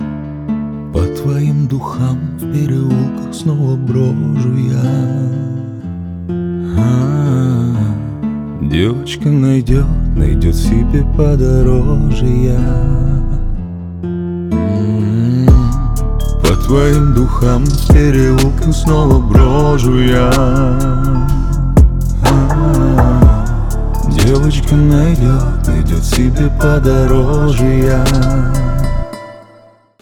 грустные
поп